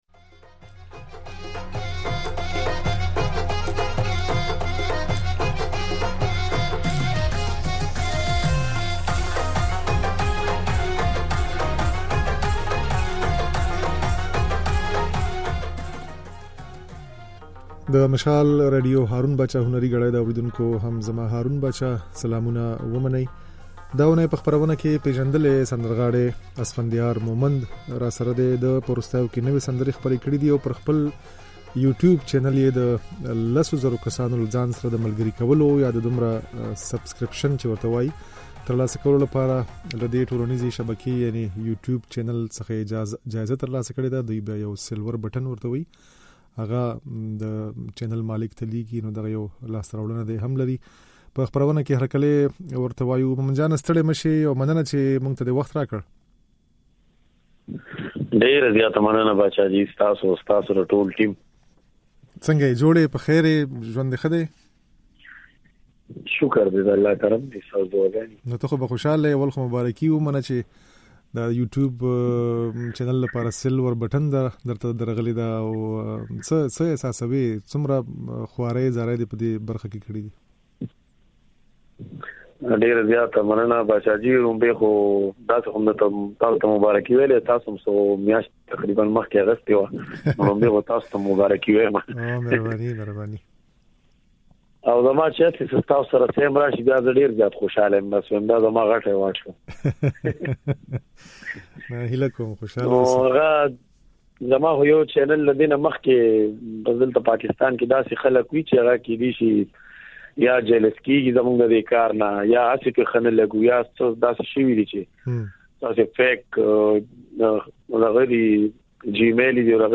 ځينې نوې سندرې يې د غږ په ځای کې اورېدای شئ.